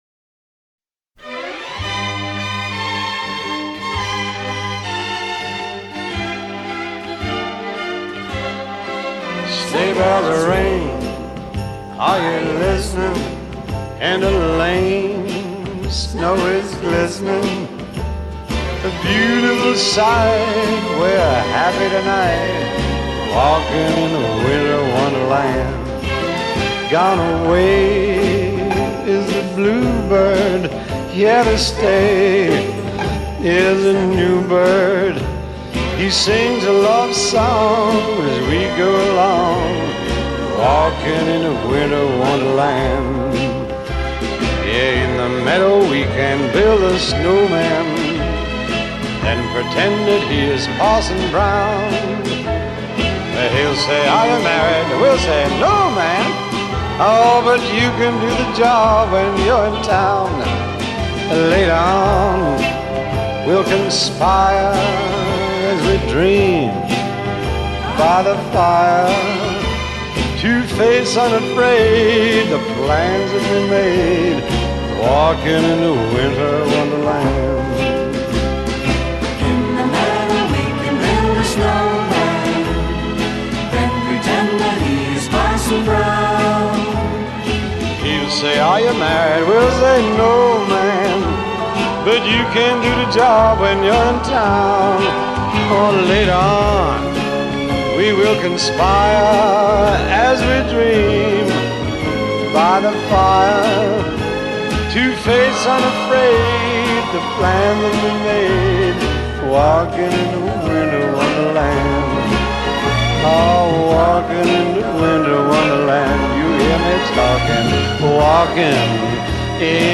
Pop, Holiday, Jazz